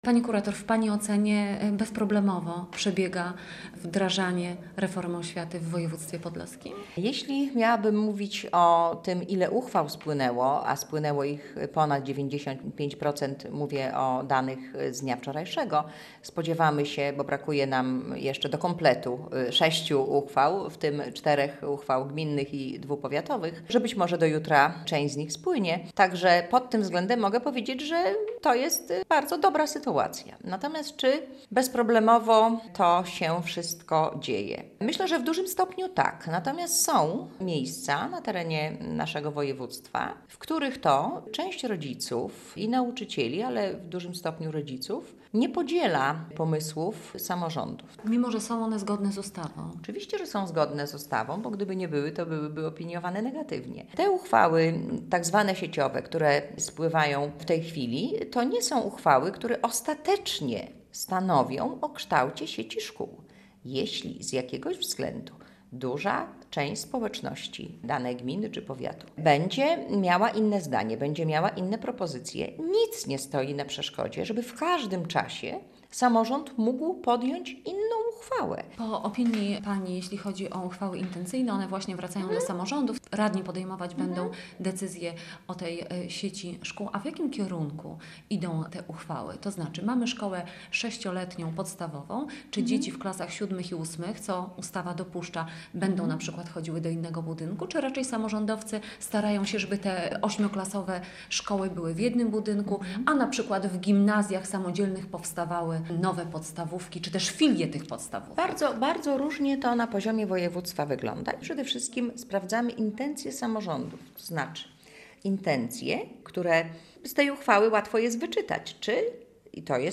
podlaski kurator oświaty